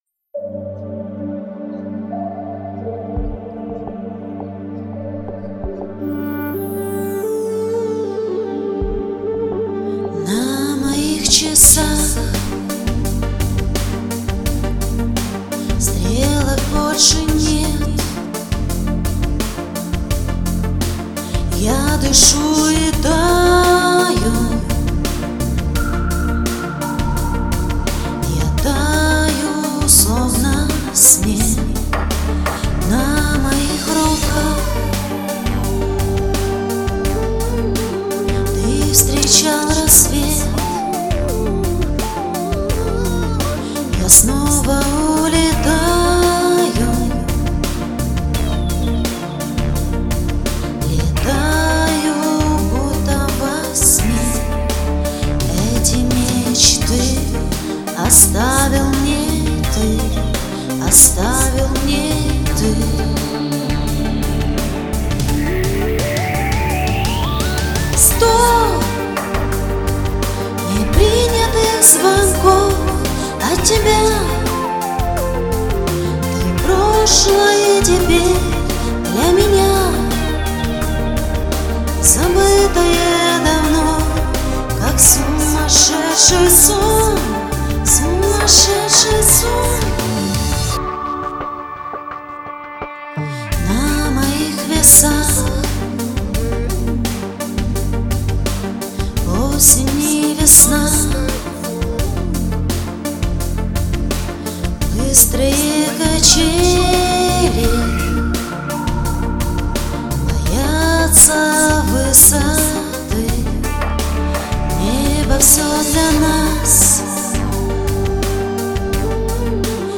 Красивая песня! Очень.Особенно,когда скрипка в коде,эх....